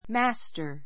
mǽstər マ ス タ